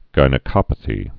(gīnĭ-kŏpə-thē, jĭnĭ-)